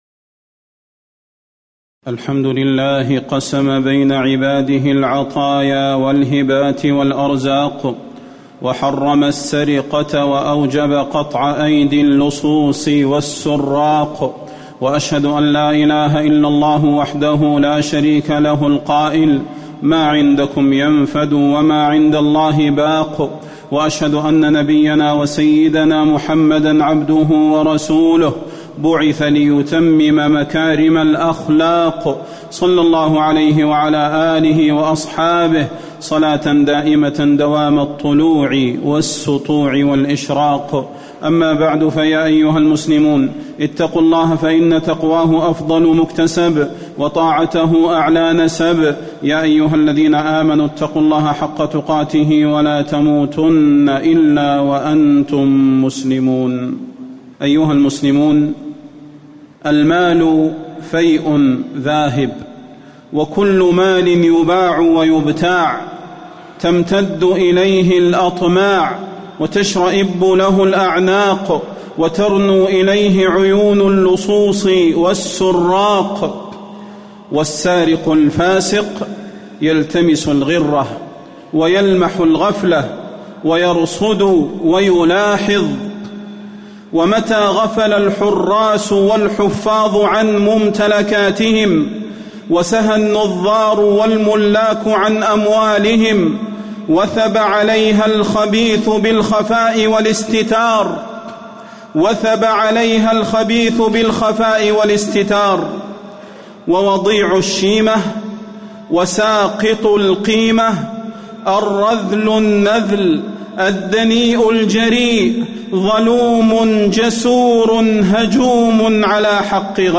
تاريخ النشر ١٤ صفر ١٤٣٩ هـ المكان: المسجد النبوي الشيخ: فضيلة الشيخ د. صلاح بن محمد البدير فضيلة الشيخ د. صلاح بن محمد البدير عقوبة السارق في الدنيا والآخرة